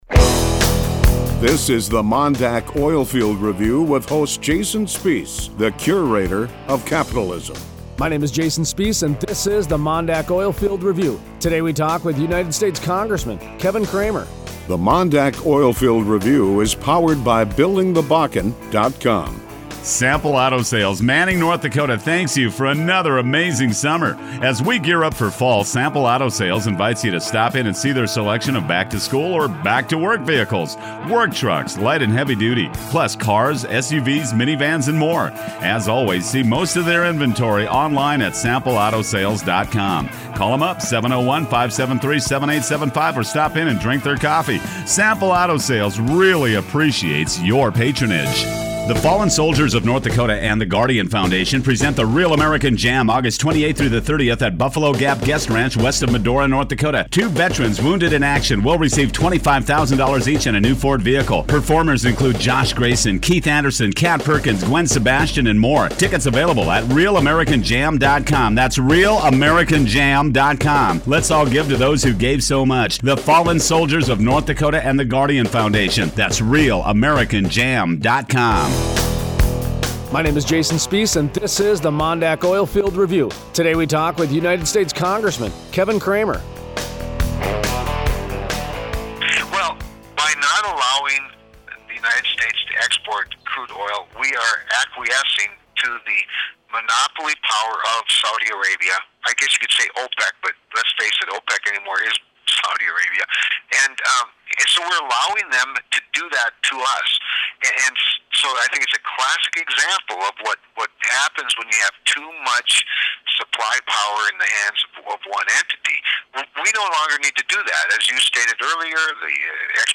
Tuesday 8/25 Interview: US Congressman Kevin Cramer (R-ND) Cramer opines on the US Crude Oil Export Ban. He believes lifting the ban would create new economic activity in the borders of the US while positioning the US as a player in the global energy marketplace.